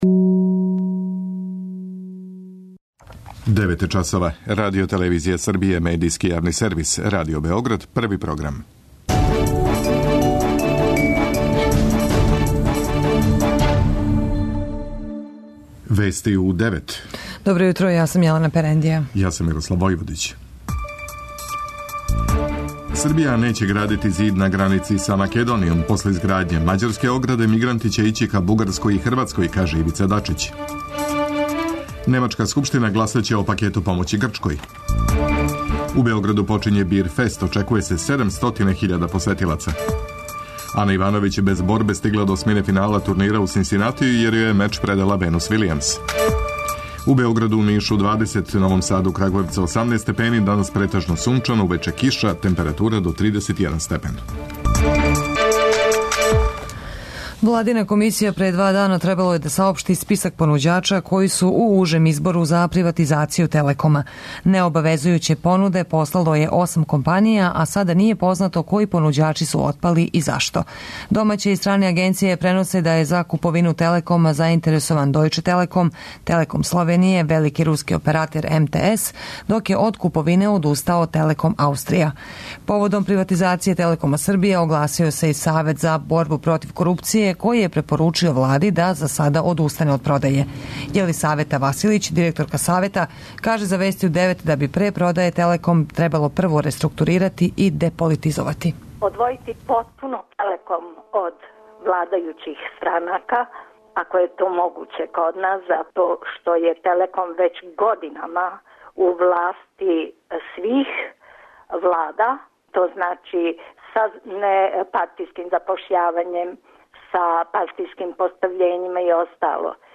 преузми : 10.30 MB Вести у 9 Autor: разни аутори Преглед најважнијиx информација из земље из света.